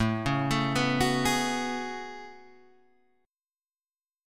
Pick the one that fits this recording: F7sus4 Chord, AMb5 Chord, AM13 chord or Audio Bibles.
AM13 chord